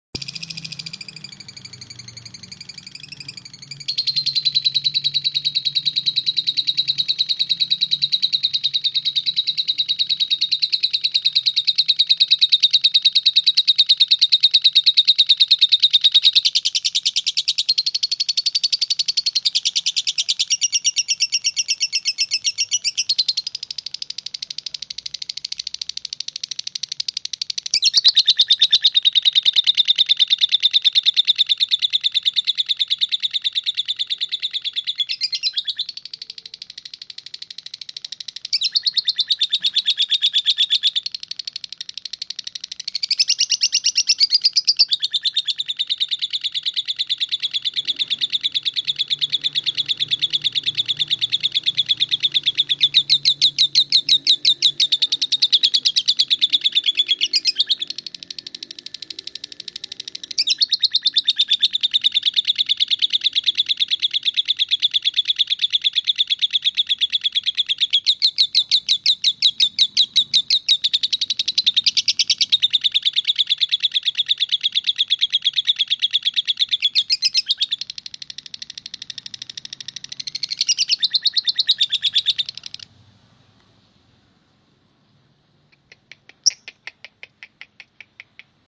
Download Suara Burung Lovebird Gacor Durasi Panjang
Kicau Lovebird Zupiter | Download